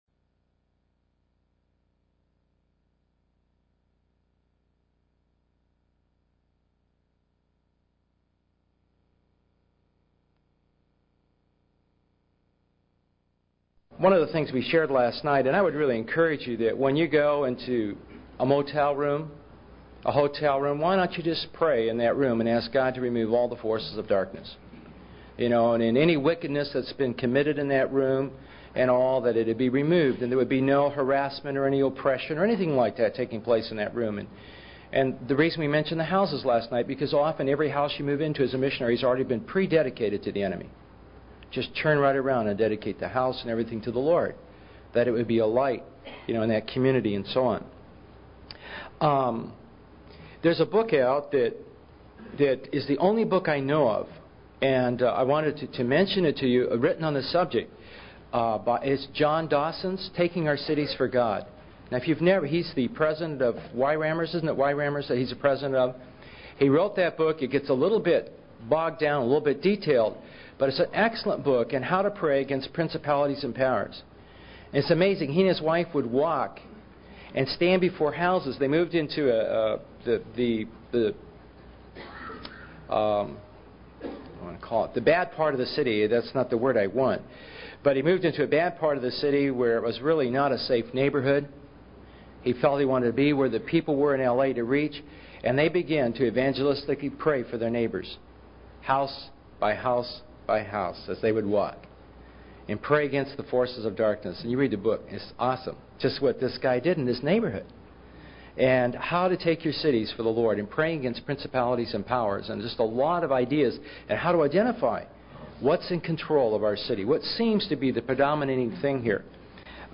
In this sermon, the speaker shares a story about a man who was robbed at gunpoint in a New York subway.